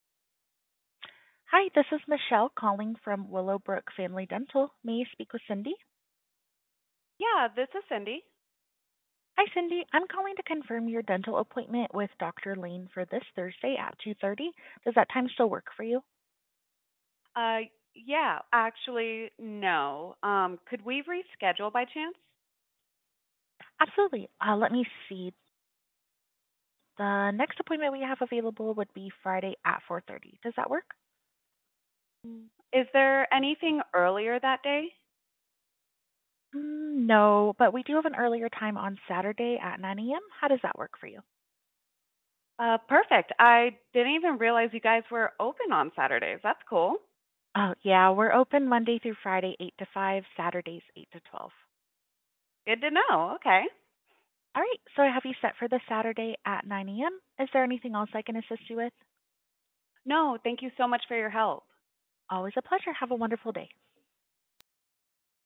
Appointment Confirmation
HUMAN RECEPTIONIST
OB-Appointment-Confirmation-Human.mp3